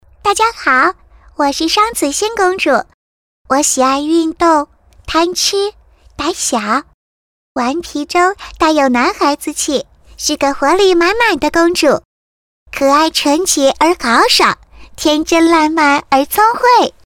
女国112_专题_政府_高新区_大气-新声库配音网
配音风格： 大气 知性 时尚 稳重 亲切 轻松 年轻 活力 力度 温柔 力度 温柔